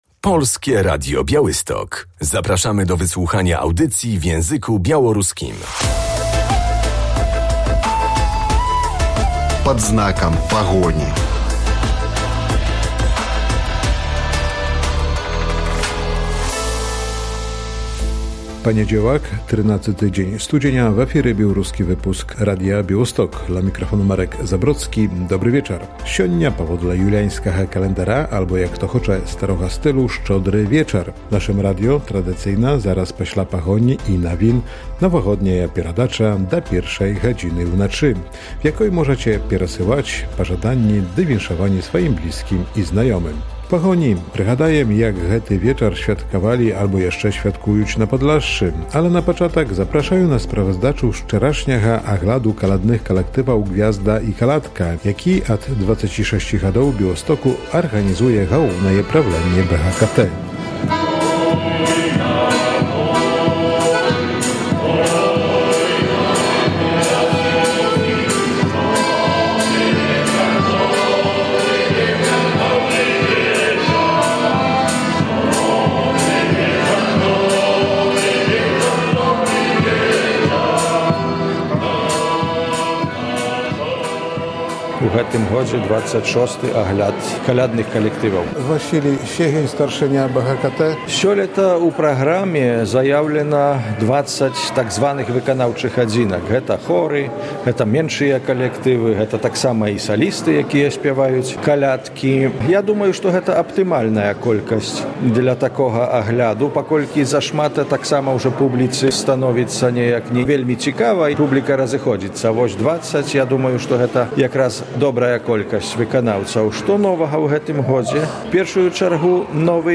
Prawosławne kolędy i pastorałki śpiewane przez dzieci, młodzież i dorosłych można było usłyszeć w niedzielę (12.01) po południu podczas przeglądu "Gwiazda i Kolęda" w Białymstoku. Impreza organizowana jest przez Białoruskie Towarzystwo Społeczno-Kulturalne.